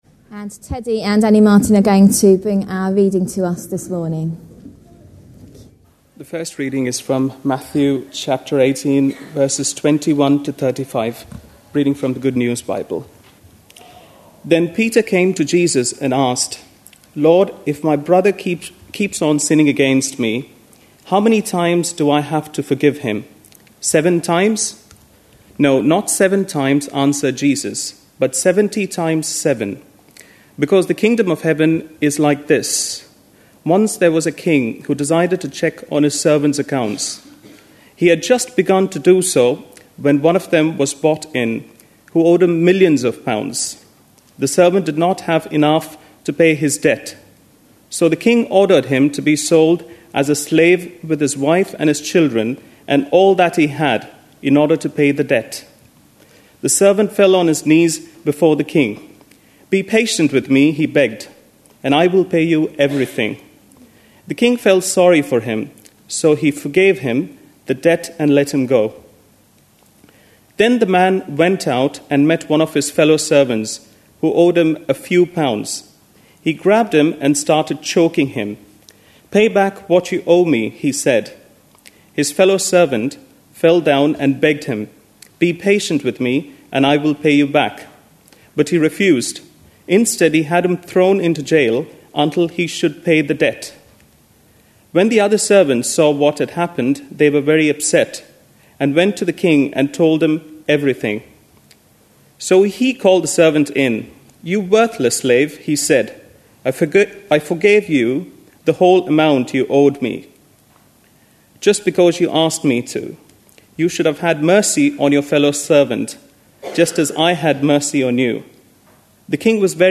A sermon preached on 14th November, 2010, as part of our Parables of Matthew series.
Matthew 18:21-35 Listen online Details Readings are Matthew 18:21-35 and Colossians 3:12-14. (Service was held on Remembrance Sunday, and at one point a marching band passed outside the building.)